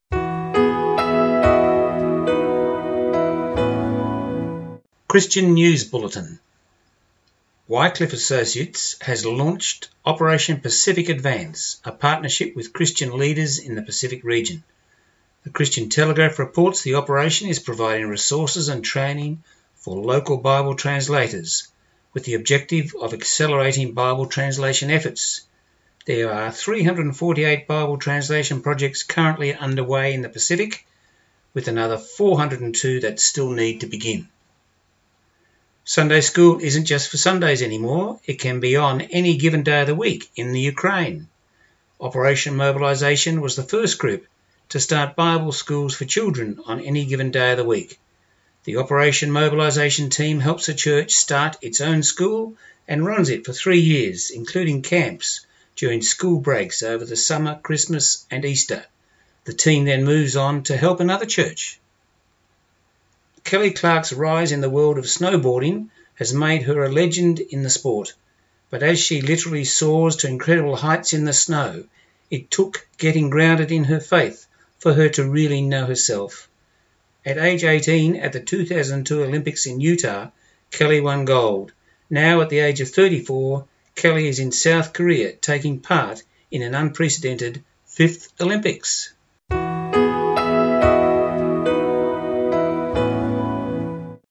18Feb18 Christian News Bulletin